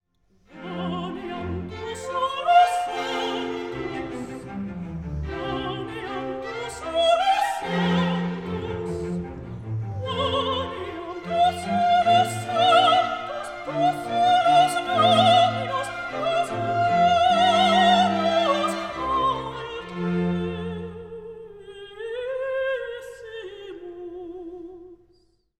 But just as quickly, the music springs up again in triple time for “
Quoniam“, a sprightly ingenuous song for soprano.  As in the “K139” “Quoniam”, the presence of melismas does not make this “operatic”; for me it suggests a physical “twirling” as the soprano sings a gentle, open folk song.